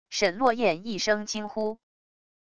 沈落雁一声惊呼wav音频